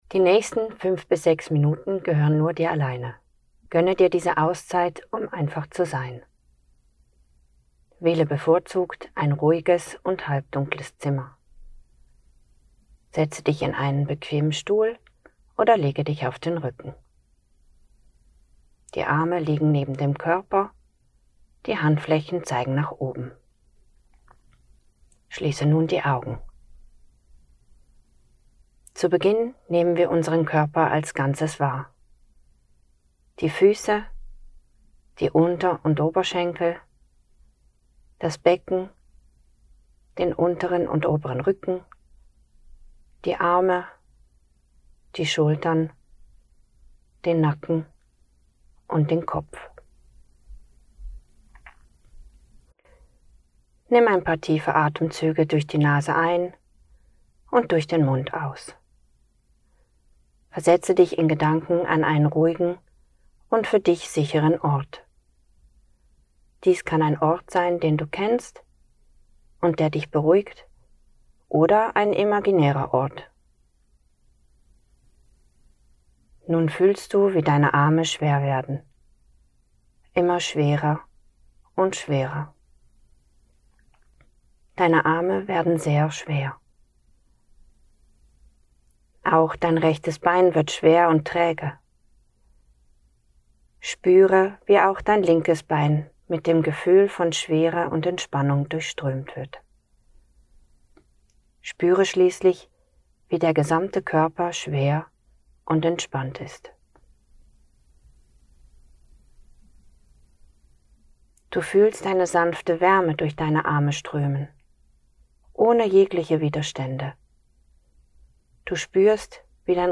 Autogenes Training ohne Musik